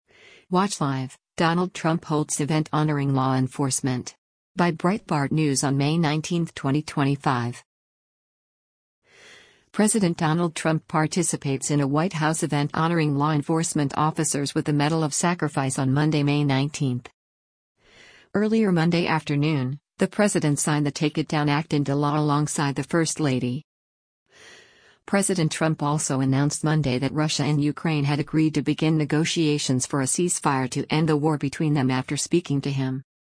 President Donald Trump participates in a White House event honoring law enforcement officers with the Medal of Sacrifice on Monday, May 19.